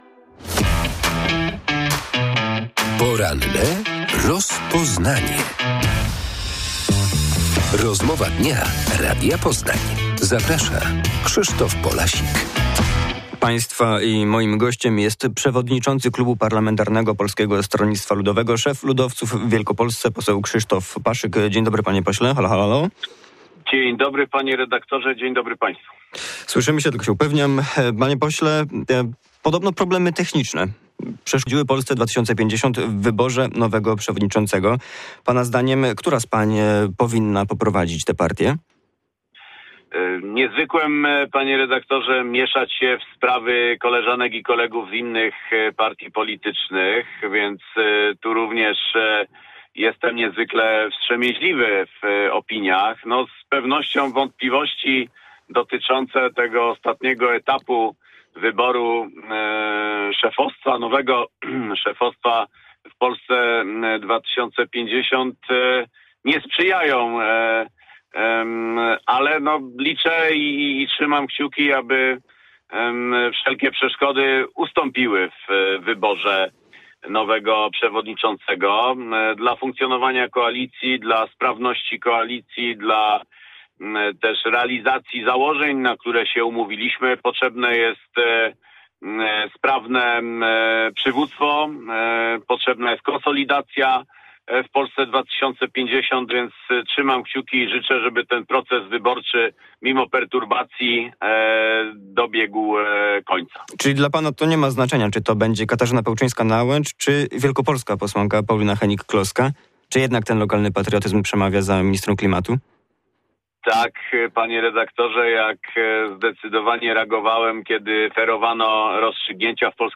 s71yxaw7b3lse5e_paszyk_poranna_rozmowa.mp3